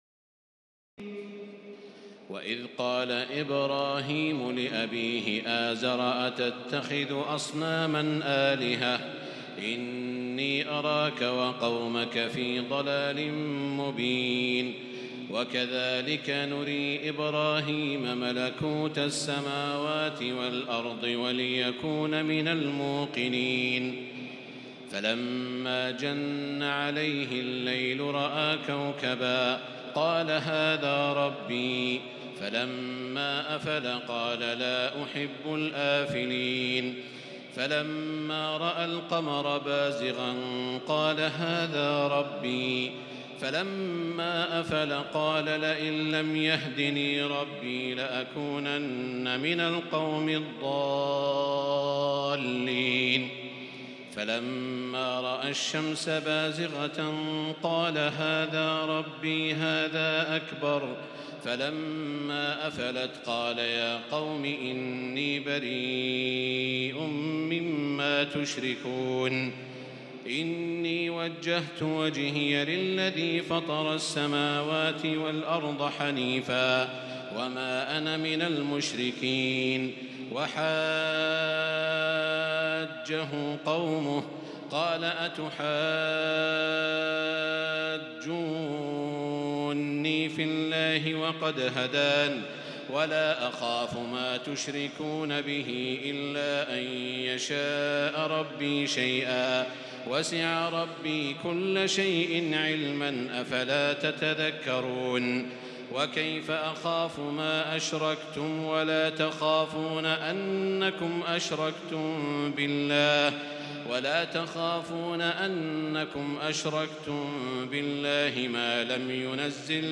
تراويح الليلة السابعة رمضان 1438هـ من سورة الأنعام (74-144) Taraweeh 7 st night Ramadan 1438H from Surah Al-An’aam > تراويح الحرم المكي عام 1438 🕋 > التراويح - تلاوات الحرمين